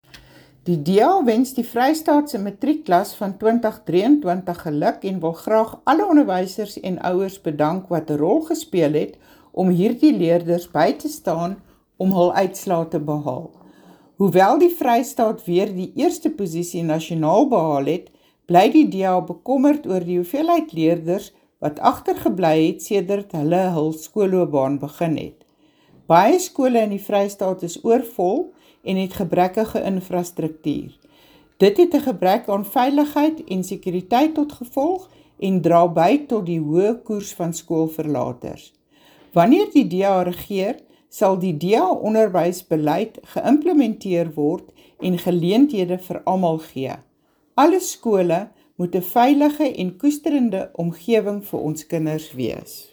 Afrikaans soundbites by Mariette Pittaway MPL and Sesotho soundbite by Jafta Mokoena MPL